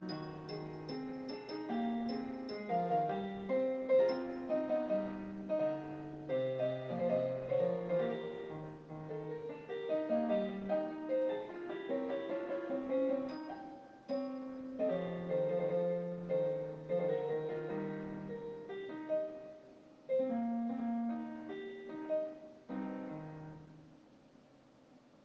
Also, the quality is pretty poor, because I recorded it with my smartphone, to show it some friends.
note the high pitch notes the LSTM added: